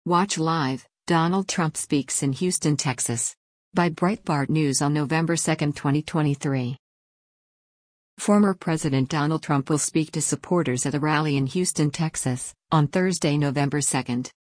Former President Donald Trump will speak to supporters at a rally in Houston, Texas, on Thursday, November 2.